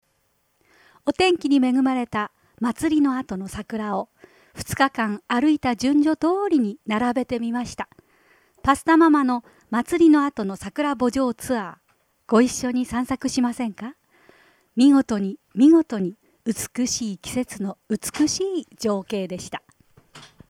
ナレーション